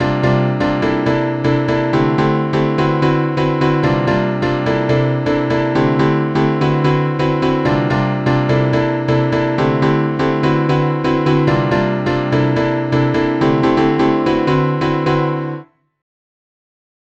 (quick QAED recording example)
5. If using Suno - it doesn't matter that Easy Cool Chords uses piano.
cool_chords_QAED_recording.wav